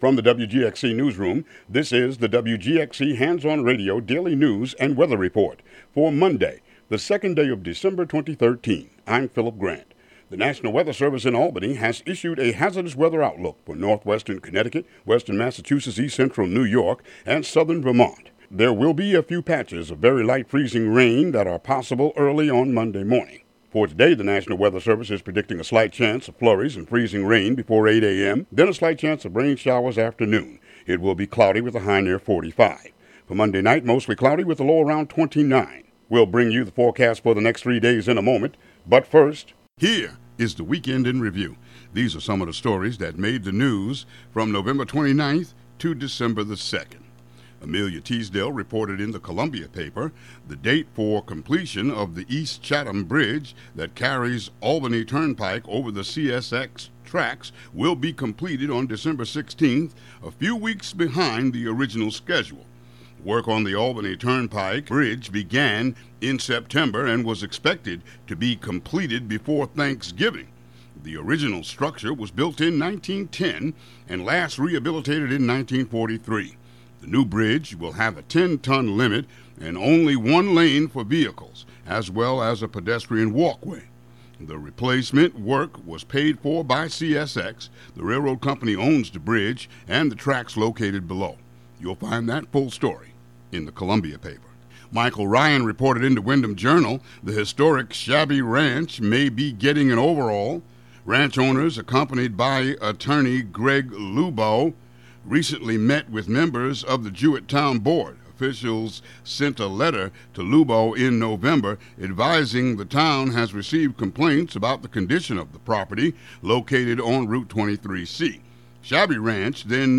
Local news and weather for Monday, December 2, 2013.